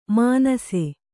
♪ mānase